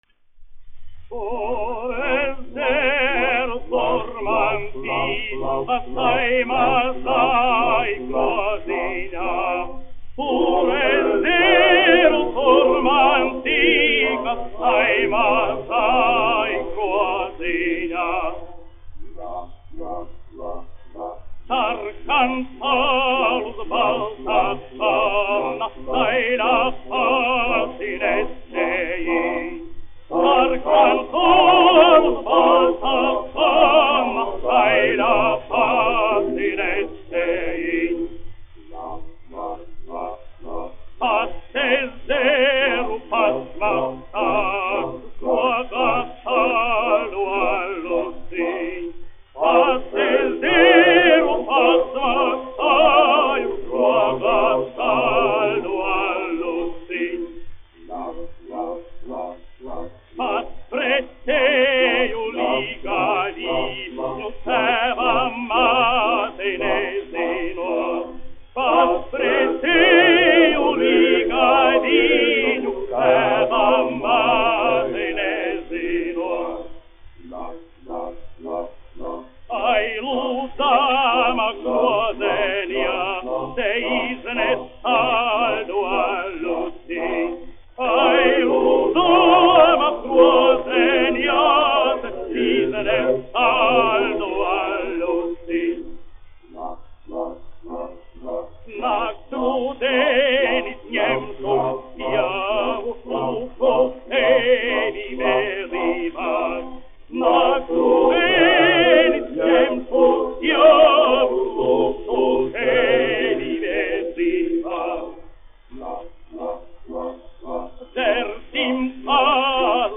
1 skpl. : analogs, 78 apgr/min, mono ; 25 cm
Latviešu tautasdziesmas
Skaņuplate
Latvijas vēsturiskie šellaka skaņuplašu ieraksti (Kolekcija)